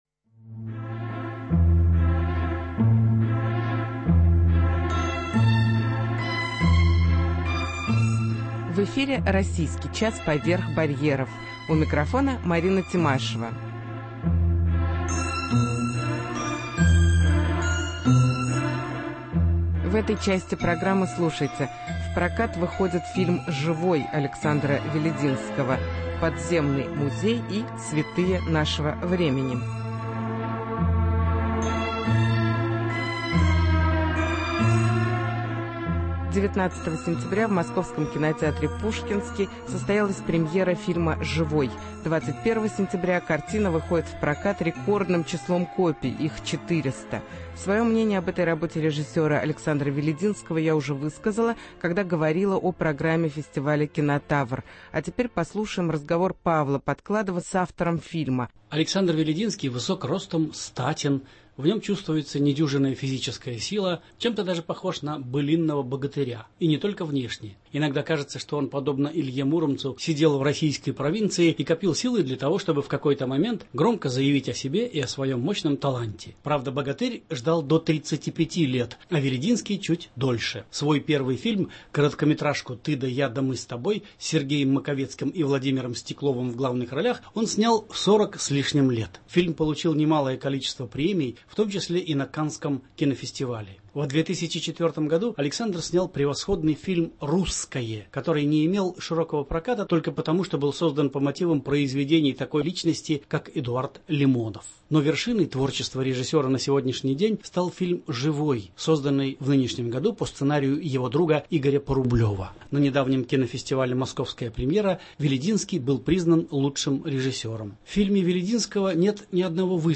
Интервью с режиссером Александром Велединским